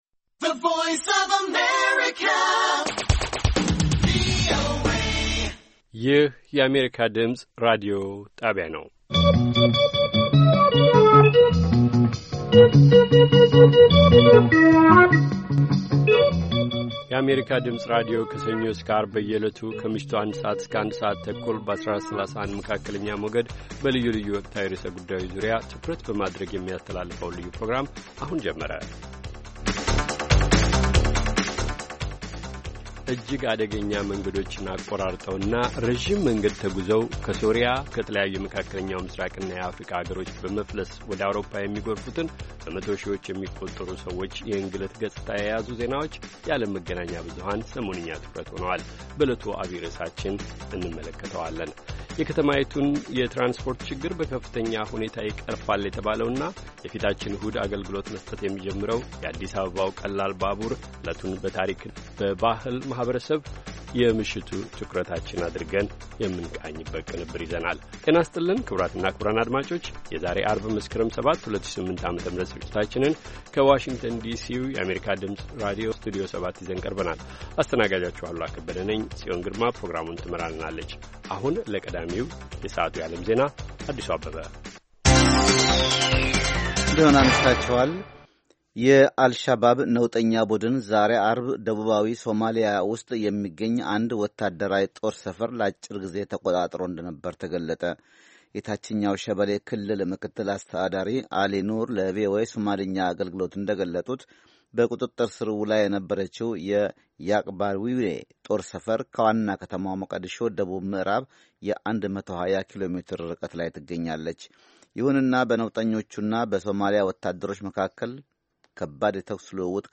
ከምሽቱ አንድ ሰዓት የአማርኛ ዜና